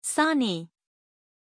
Aussprache von Sani
pronunciation-sani-zh.mp3